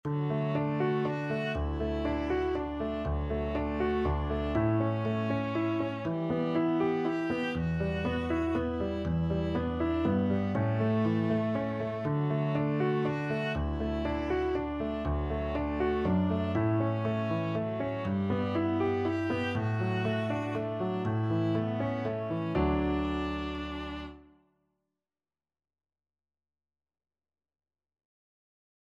Viola
D major (Sounding Pitch) (View more D major Music for Viola )
Gently = c.120
3/4 (View more 3/4 Music)
Traditional (View more Traditional Viola Music)